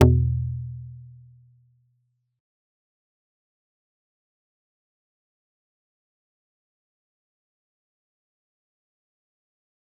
G_Kalimba-E2-mf.wav